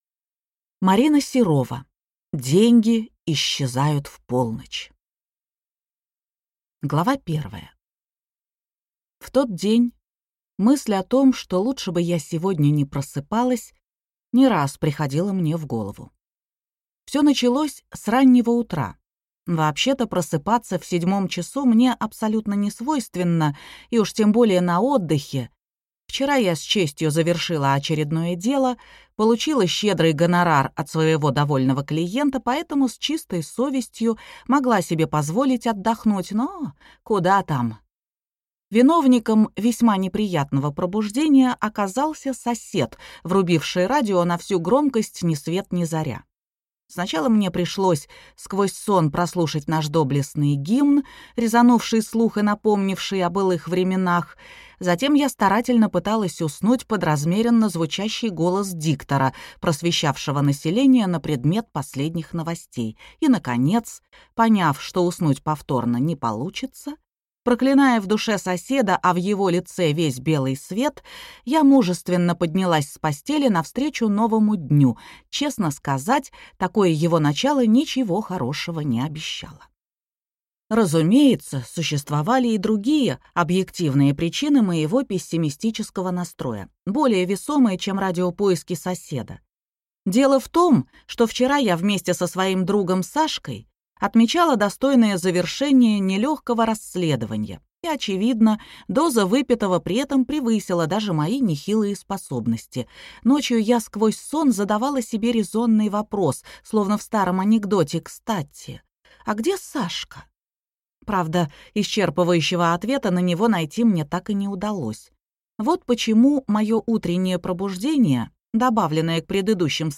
Аудиокнига Деньги исчезают в полночь | Библиотека аудиокниг